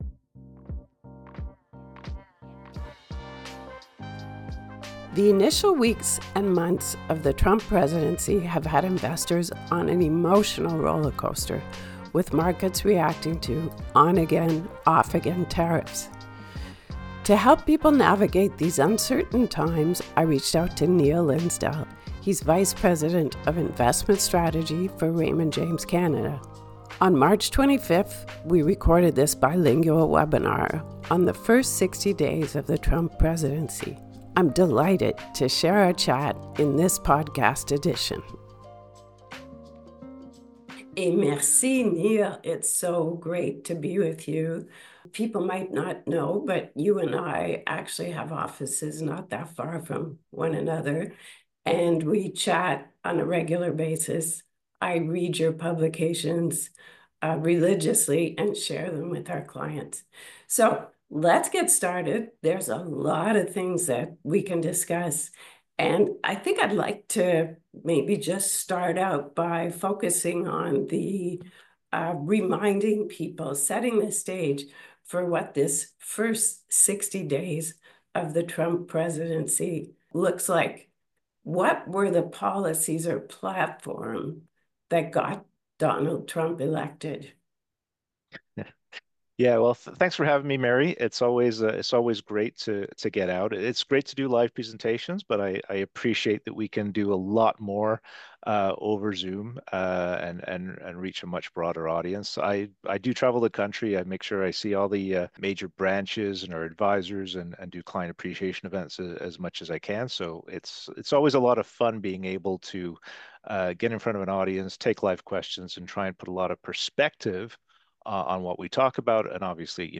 On March 25th we recorded this bilingual webinar on the First 60 days of the Trump presidency.